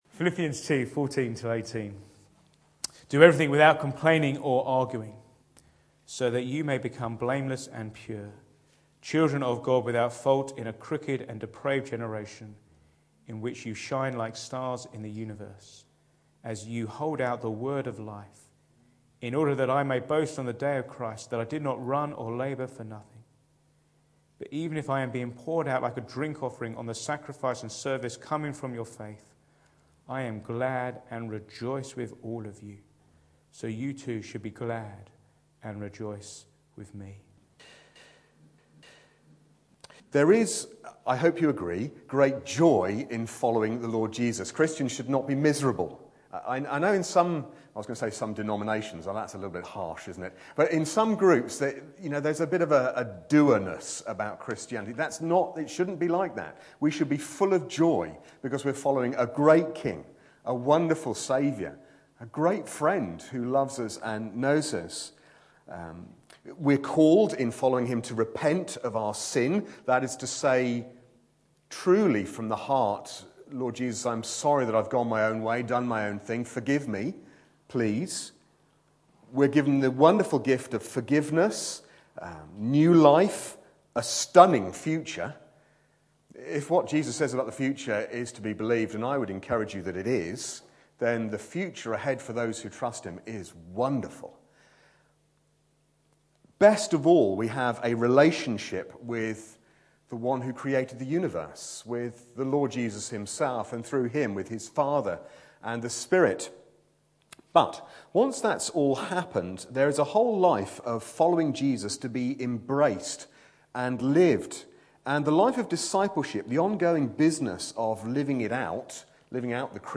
Back to Sermons Radical Outreach – How do I shine?